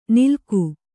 ♪ nilku